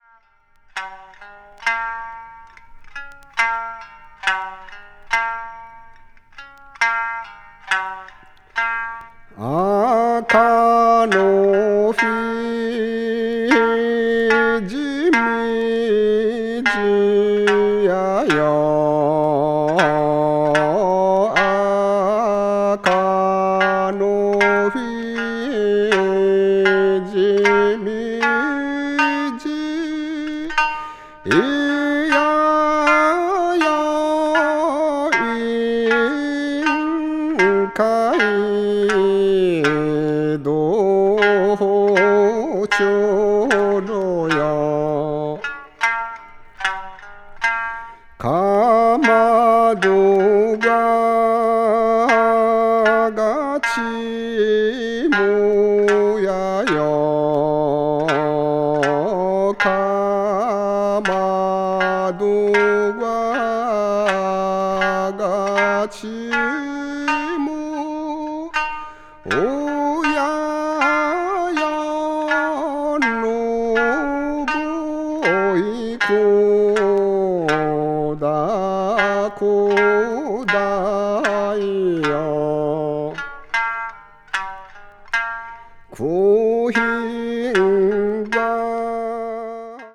録音も非常に秀逸。
ethnic music   japan   minyo   okinawa   ryukyu   traditional